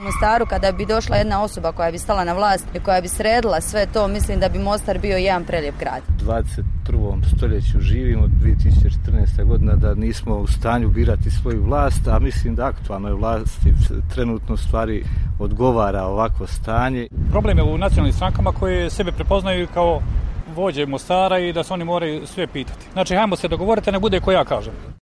Sami građani Mostara, barem oni sa kojima smo razgovarali, čini se da su umorni od politike i već višegodišnjeg zastoja Mostara u političkom, ekonomskom i svakom drugom smislu:
Mostarci o situaciji u gradu